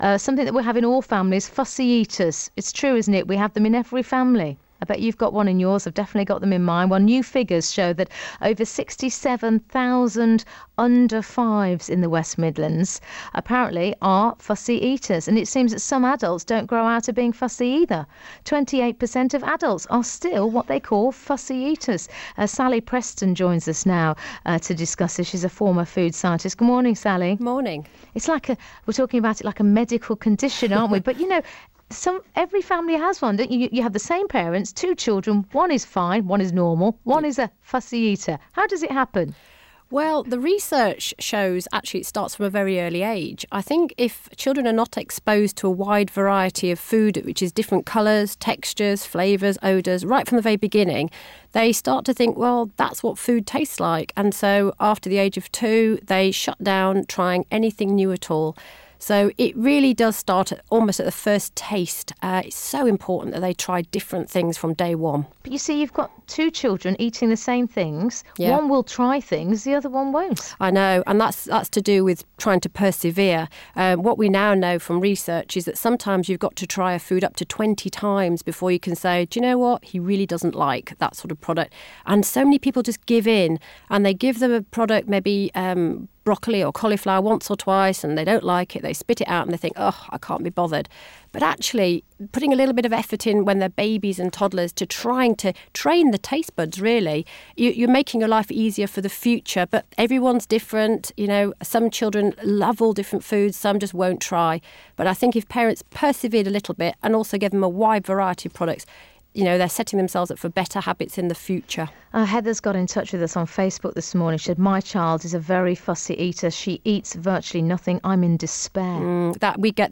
Interview on fussy eaters for Parental Advice Week